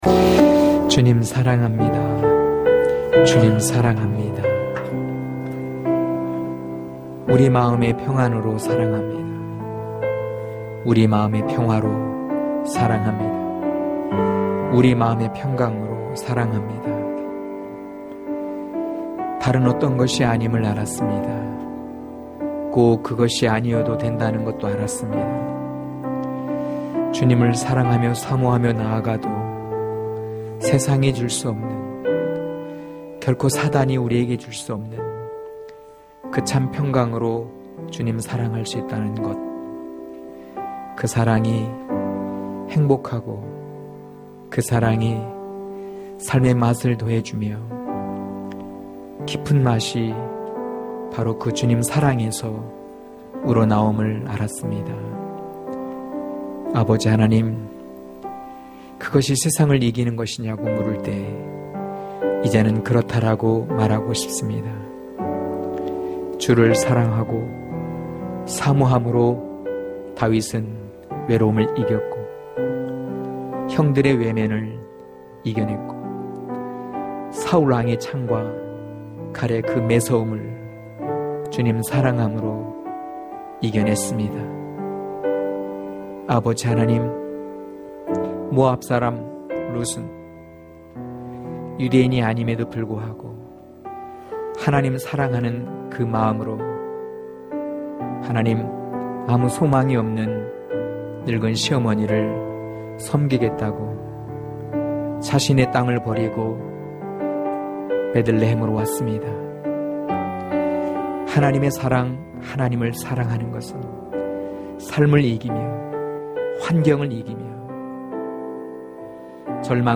강해설교 - 7.하나님의 영의 날이 오리라(요일3장21-46절)